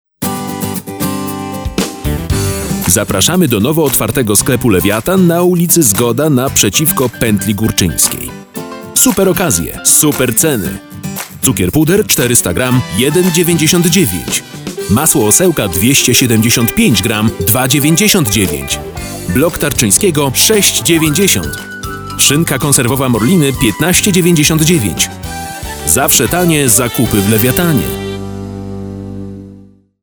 Sprechprobe: Werbung (Muttersprache):
Warm voice for all possible production types.